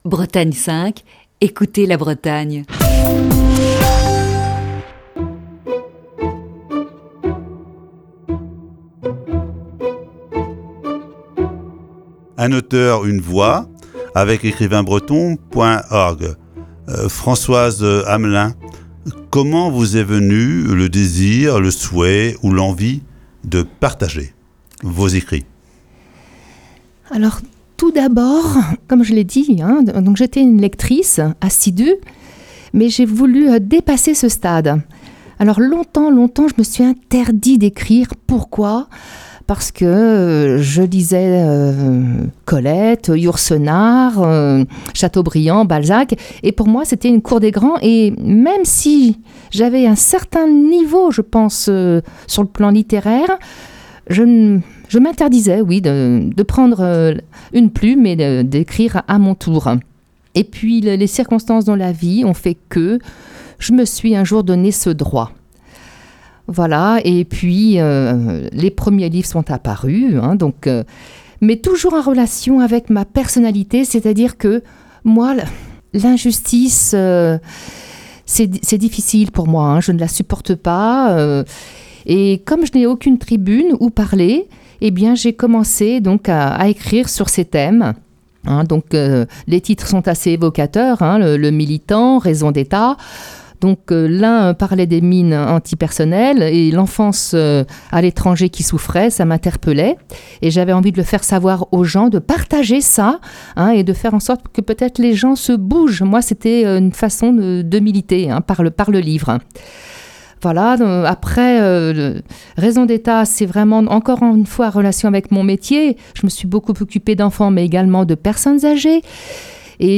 Ce jeudi, voici la quatrième partie de cet entretien. (Chronique diffusée initialement le 7 novembre 2019).